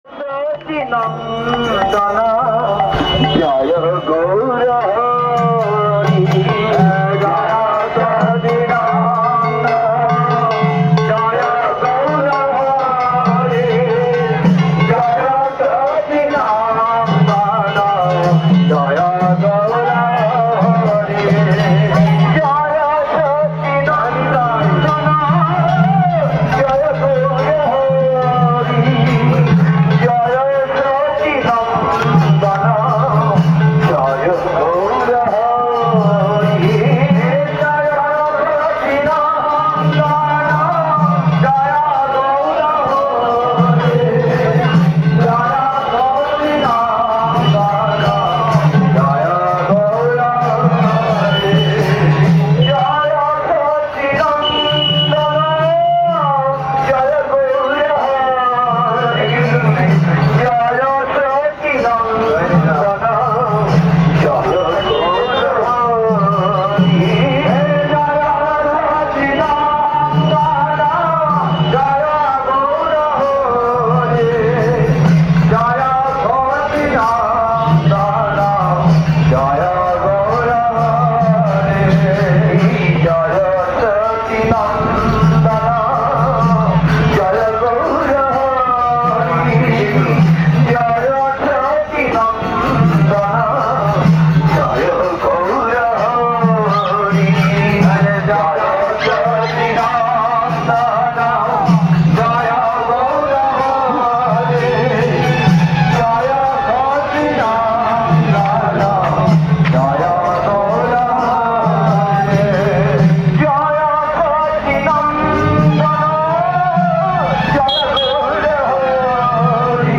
Place: SCSMath Nabadwip
Kirttan